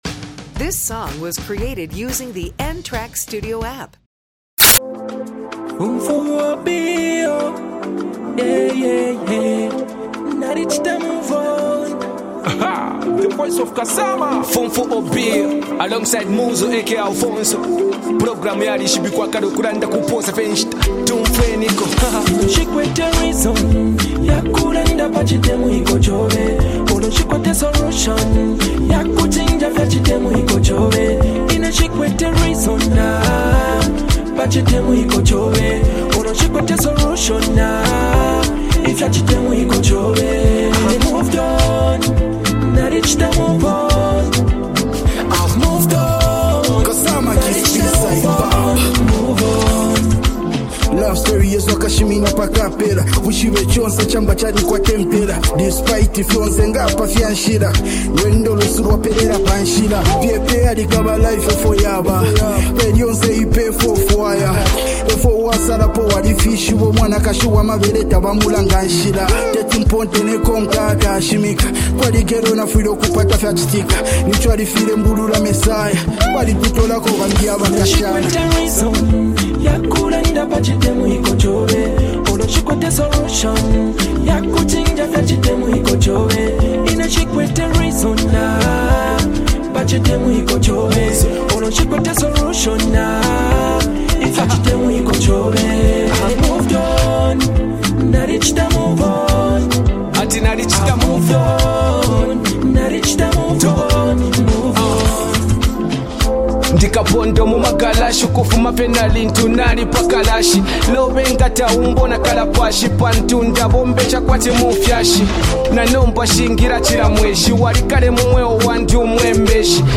reflective and emotionally grounded song
a motivational anthem